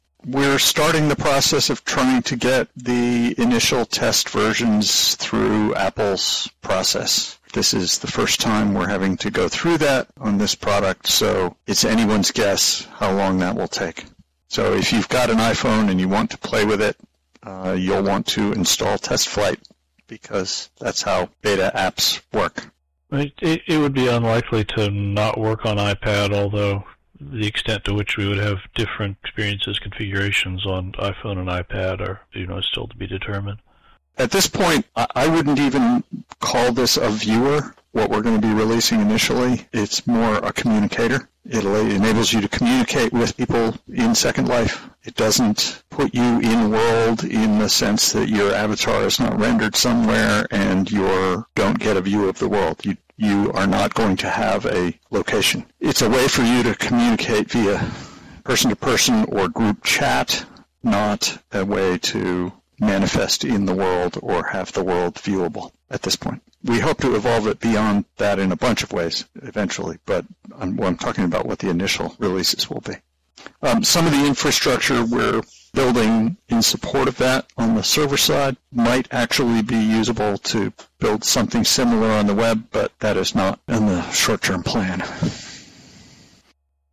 This being the case, I thought I’d offer a mini-update on the status of the app’s development, combining the comments made from both meetings into a single bullet-point list, with the relevant audio extracts from both meetings also provided for reference.